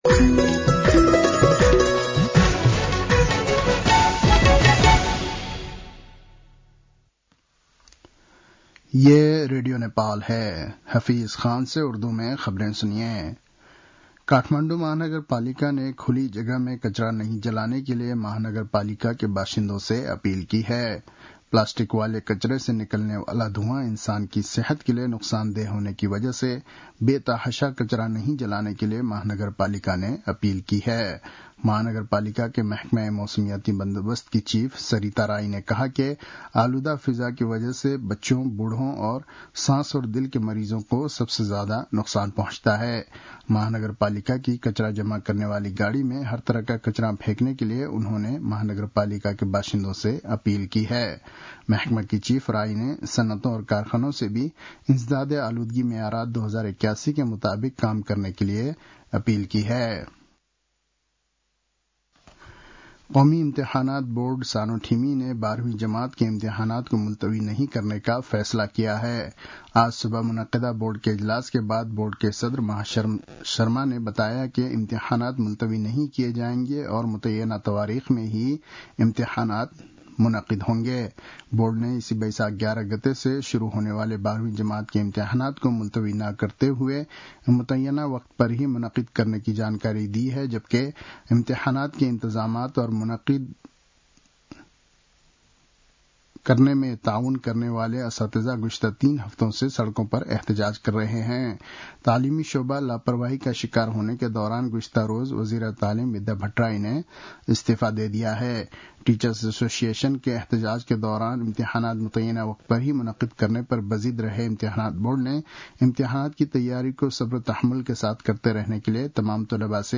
उर्दु भाषामा समाचार : ९ वैशाख , २०८२